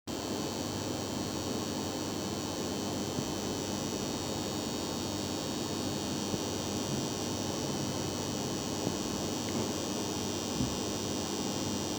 PreSonus Eris E5 XT издает скрежет
Прошу помочь с такой проблемой: Примерно спустя полтора один из двух мониторов начал издавать неприятный скрежет. Сначала я подумал, что это динамик, но после разборки оказалось, что звук идет от этой детали (трансформатора?), отметил красным, звук тоже прикладываю.